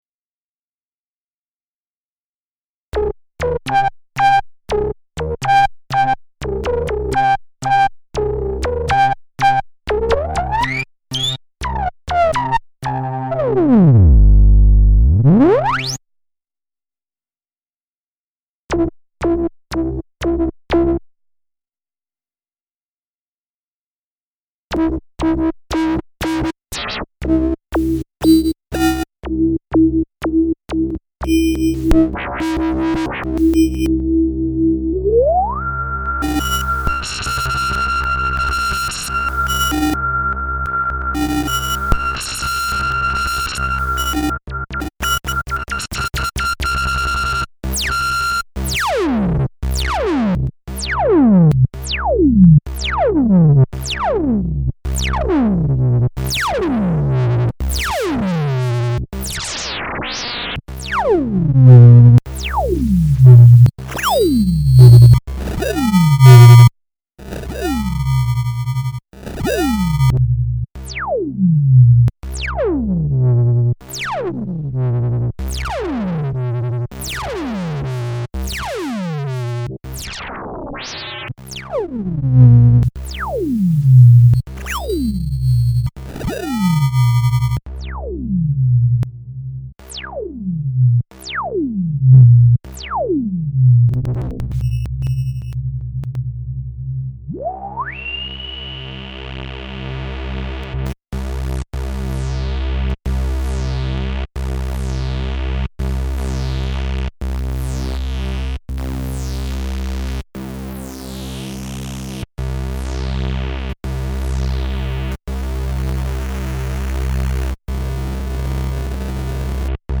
SOUND good allrounder. Effects flag ship! sort of studio standard especially for trance and neo EBM stuff
some audio, not so nice but demoes wavetables, filters and envs..
nicht "schön" aber demonstriert Wavetables, Hüllkurven Filter..